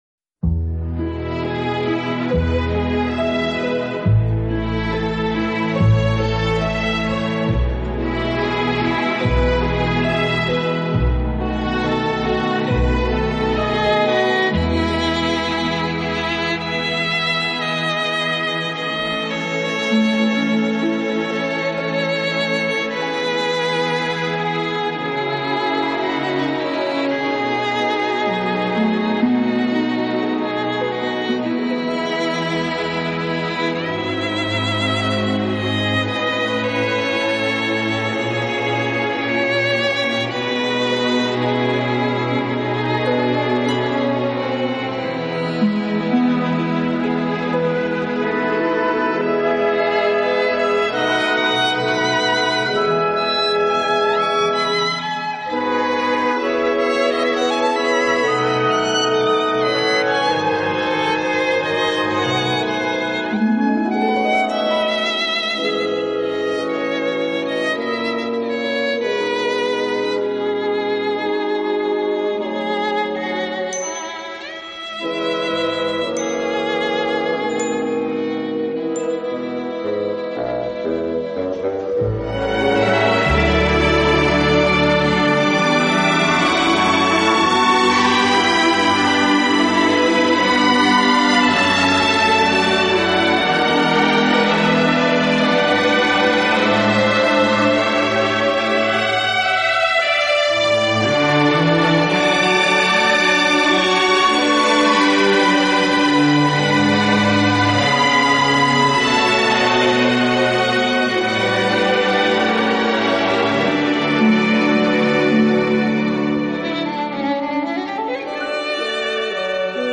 音乐风格：Celtic Music